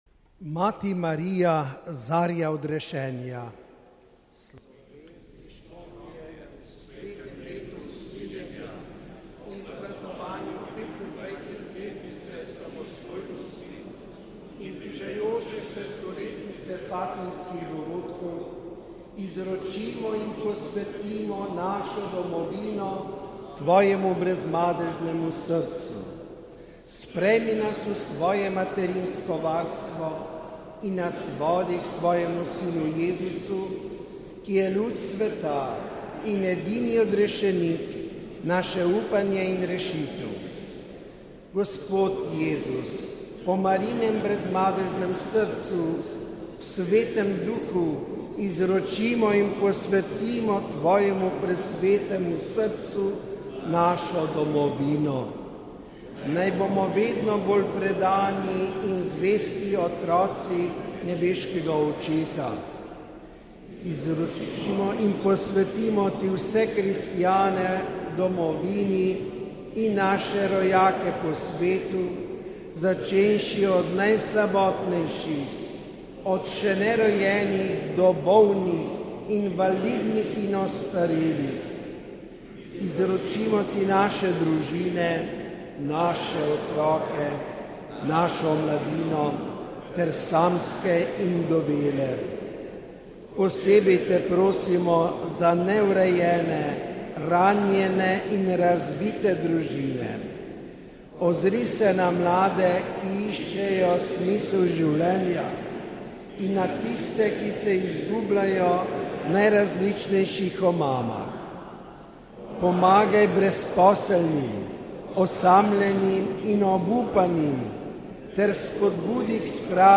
Molitev